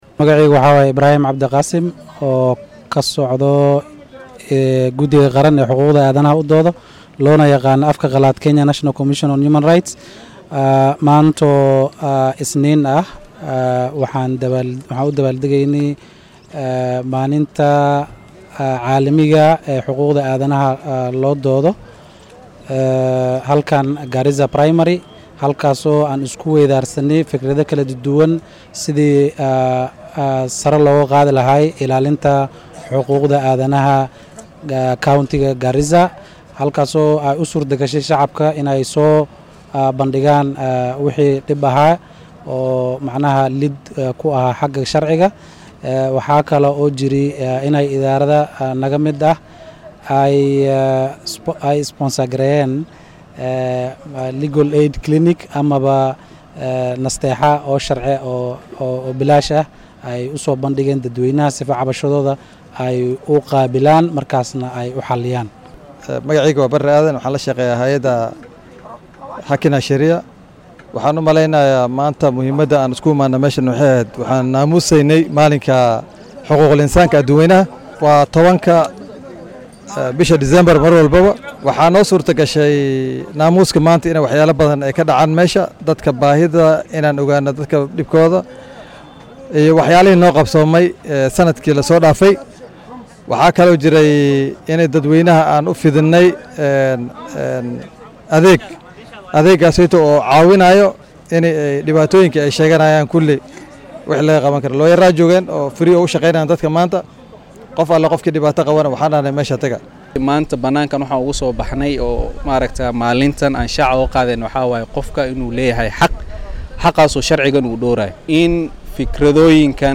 Waxaa ismaamulka Garissa lagu qabtay xuska maalinta xuquuqul aadanaha ee adduunka oo shalay ku beegnayd. Qaar ka mid ah mas’uuliyiintii soo qaban qaabiyay dabbaal deggan oo ay ka mid yihiin kuwo guddiga qaran ee xuquuqul aadanaha ee KNCHR, hay’adda Haki na Sheria iyo kuwo kale ayaa u warramay warbaahinta Star iyadoo halkaasi laga fidiyay adeegyo bilaash oo la talin dhanka sharciga ah. Dadweynaha ayaa lagu boorriyay inay soo gudbiyaan xadgudubyada ka dhanka ah xuquuqdooda.